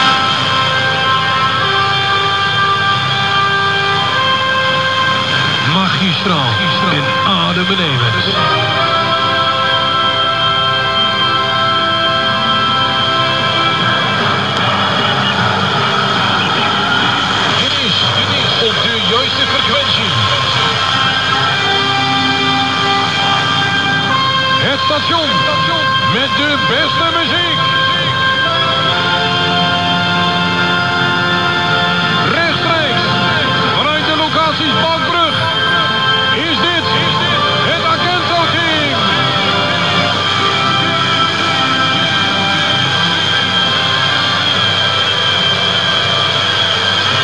1963  Shortwave delight with nine AM bands